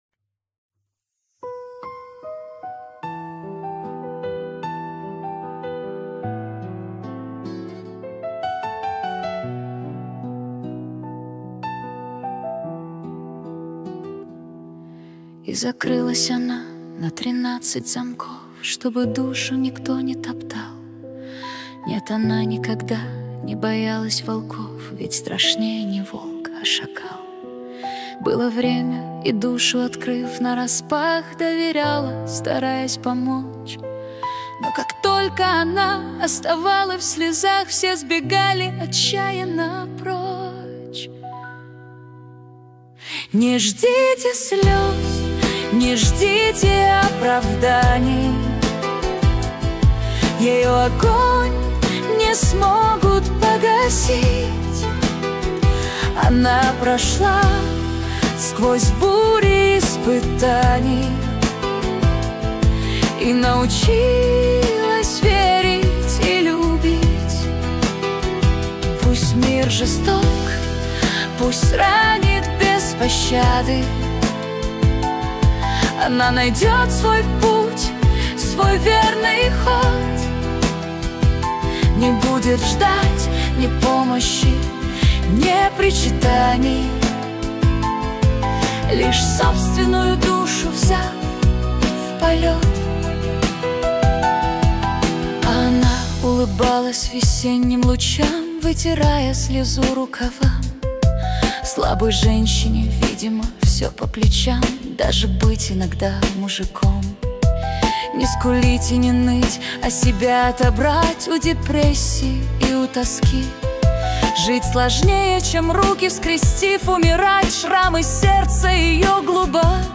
13 декабрь 2025 Русская AI музыка 198 прослушиваний